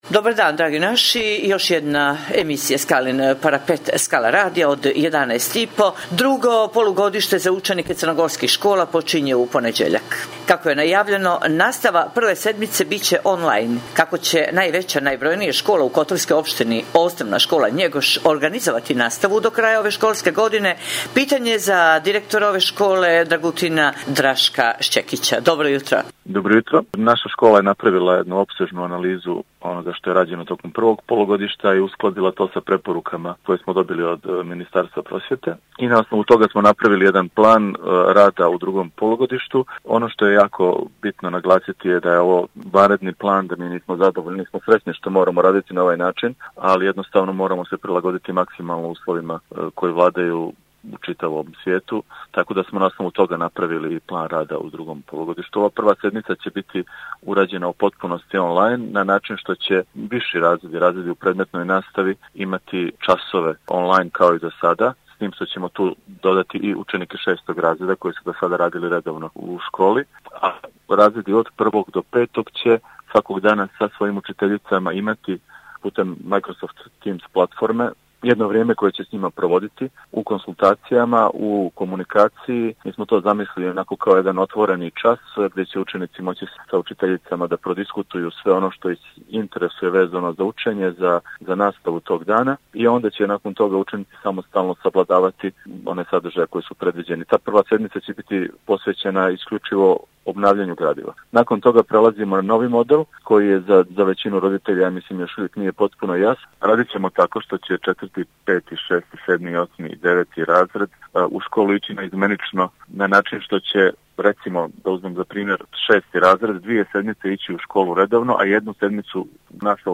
Poštujući pravila nadležnih zbog epidemije korona virusa Skala radio će emisiju “Skalin parapet” emitovati u izmijenjenoj formi i u skraćenom trajanju, budući da nema gostovanja u studiju Skala radija do daljnjeg.
Stoga ćemo razgovore obavljati posredstvom elektronske komunikacije i telefonom, kako bi javnost bila pravovremeno informisana o svemu što cijenimo aktuelnim, preventivnim i edukativnim u danima kada moramo biti doma.